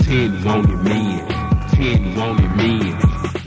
Vox